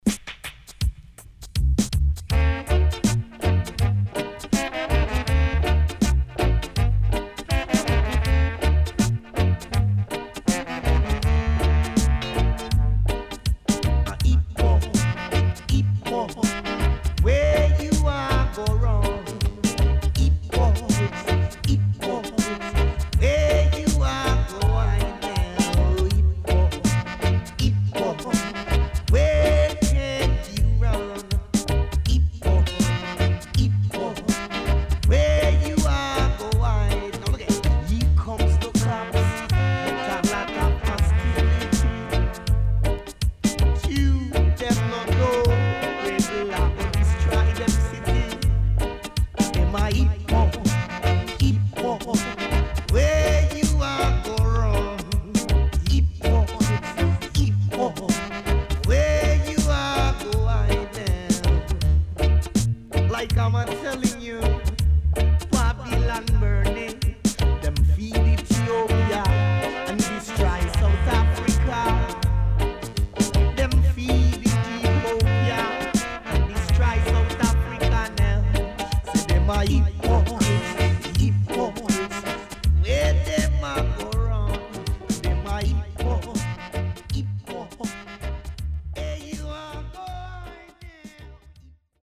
80's Digital Roots Vocal & Dubwise